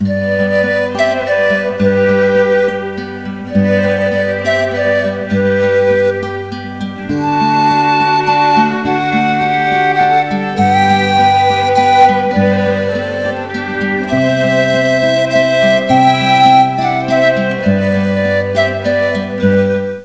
This instrumental CD features 18 popular Christmas melodies.